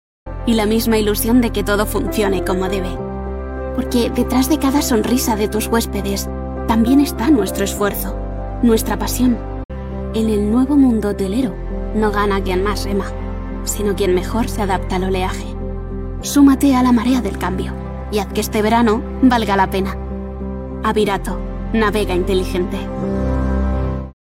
une voix off espagnole lumineuse et captivante – aiguë, vivante et polyvalente – idéale pour les publicités, l'e-learning et la narration.
Vidéos d'entreprise
Microphone : Neumann TLM 102.